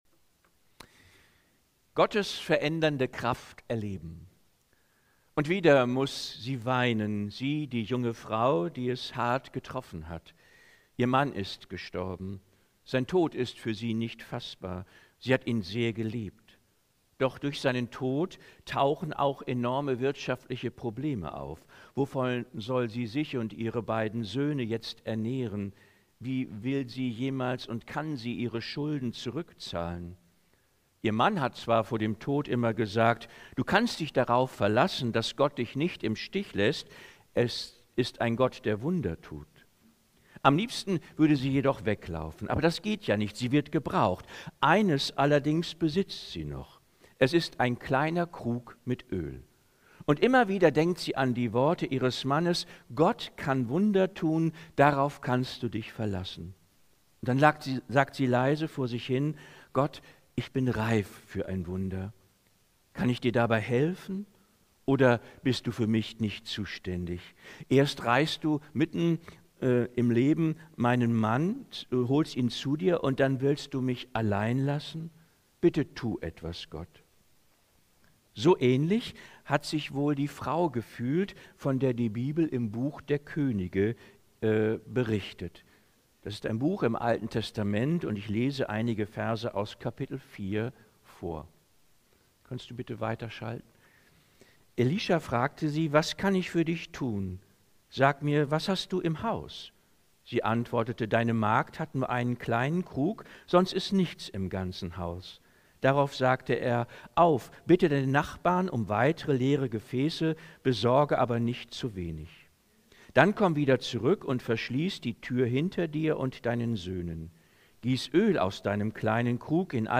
Predigten aus der Baptistengemeinde Leer